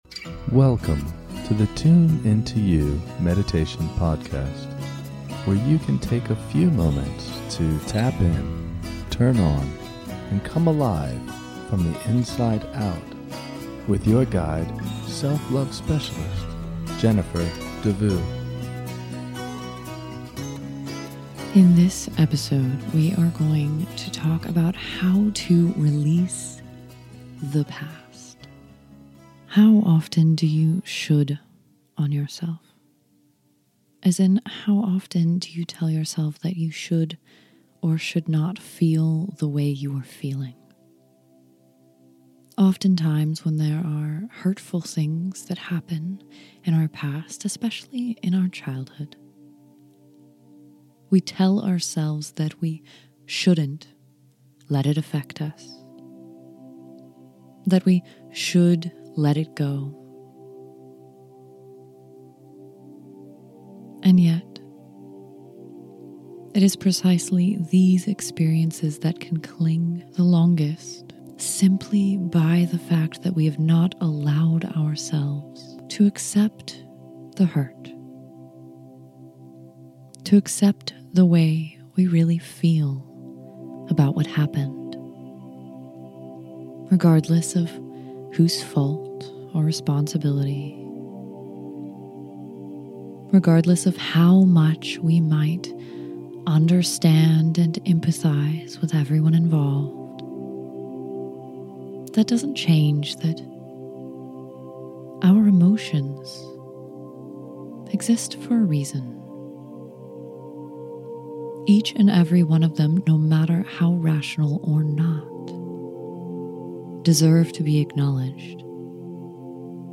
Releasing The Past Guided Meditation